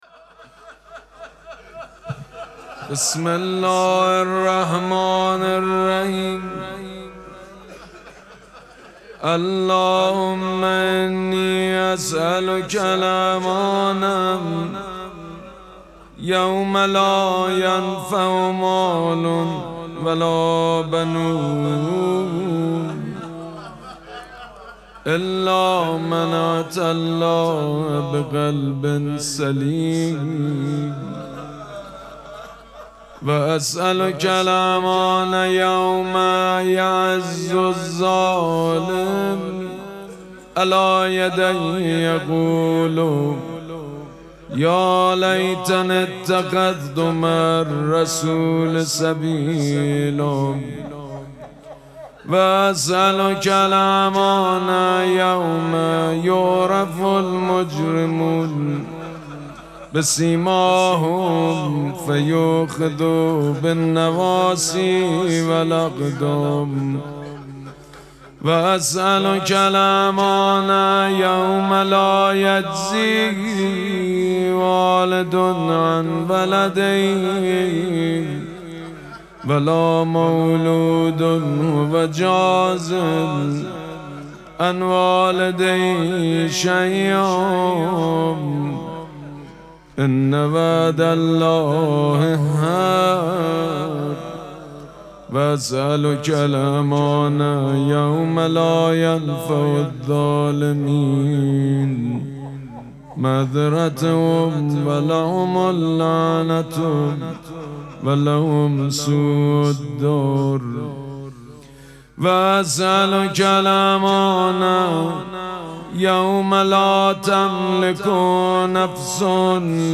مراسم مناجات شب دوازدهم ماه مبارک رمضان چهارشنبه ۲۲ اسفند ماه ۱۴۰۳ | ۱۱ رمضان ۱۴۴۶ حسینیه ریحانه الحسین سلام الله علیها
مداح حاج سید مجید بنی فاطمه